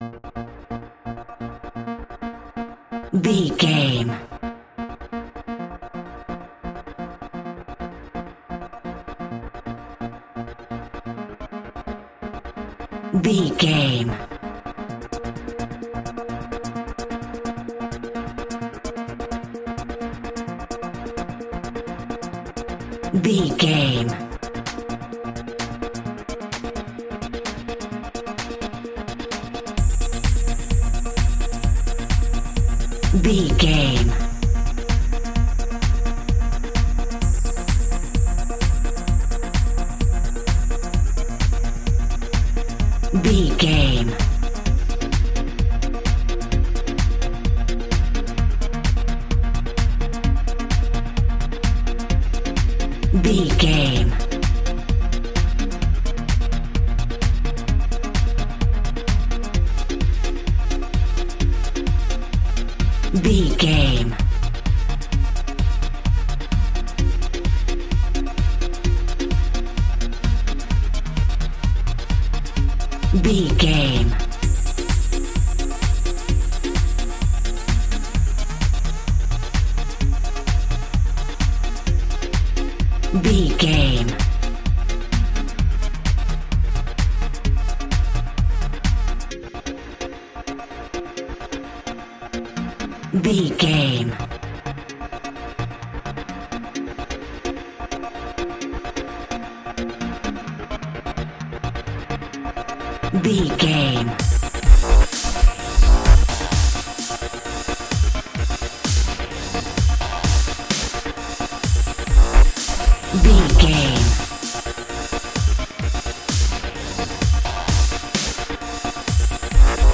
Epic / Action
Fast paced
Aeolian/Minor
B♭
aggressive
powerful
dark
driving
energetic
intense
drum machine
synthesiser
breakbeat
synth leads
synth bass